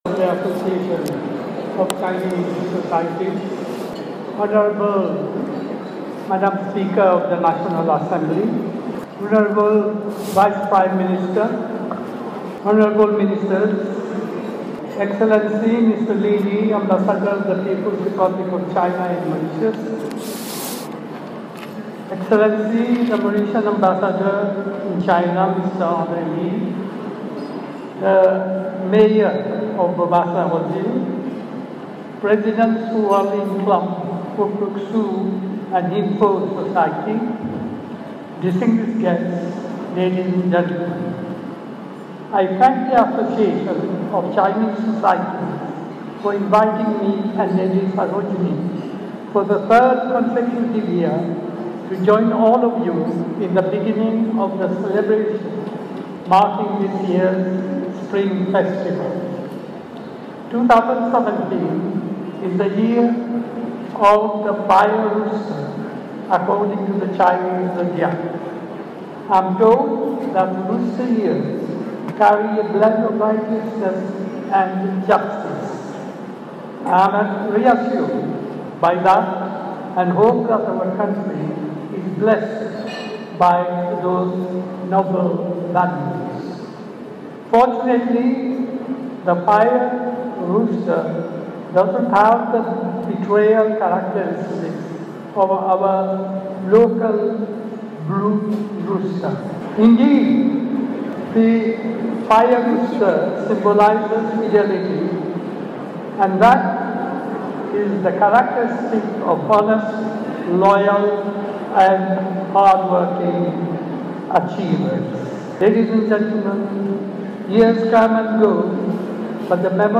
Cette allusion à peine voilée de sir Anerood Jugnauth a bien fait rire son assistance, samedi 21 janvier. Le Premier ministre se trouvait à Trianon en vue du Nouvel an chinois, placé cette fois-ci sous le signe du coq de feu.